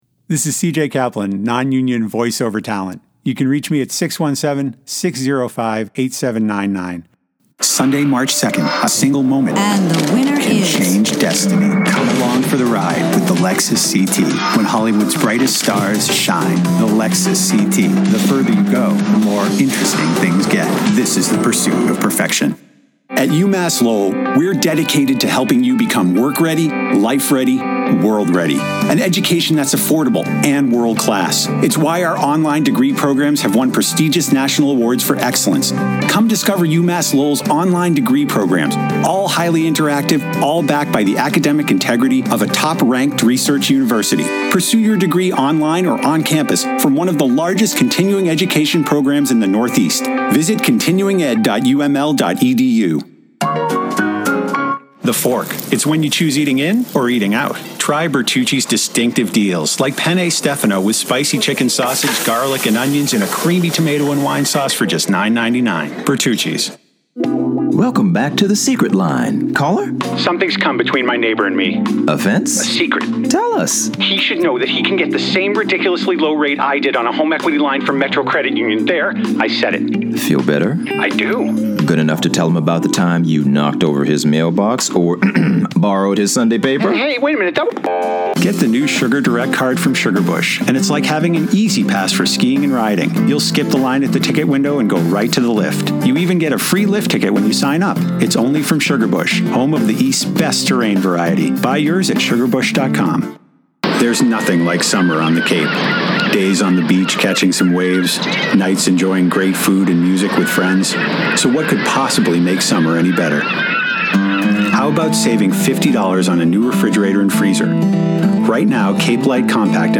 Voice Demo Reel